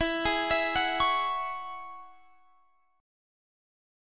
Listen to E6add9 strummed